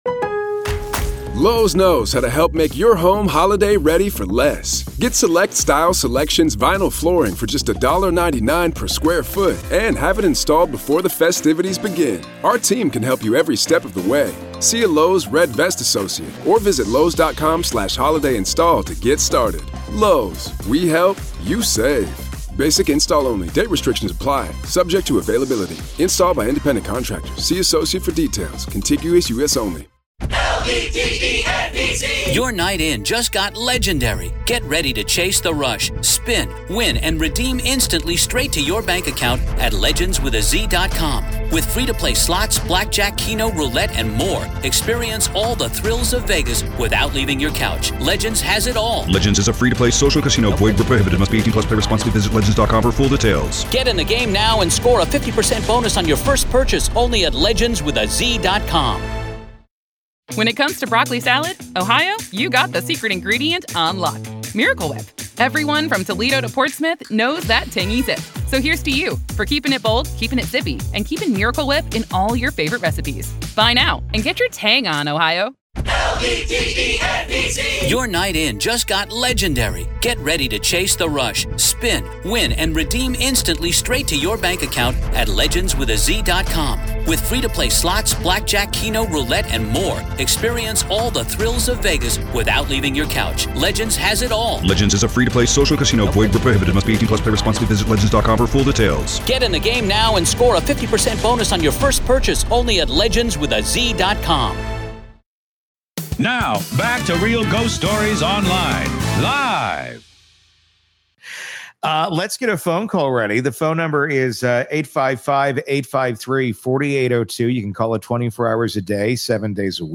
This segment is equal parts eerie and emotional — a look at how grief, numbers, and the supernatural intertwine.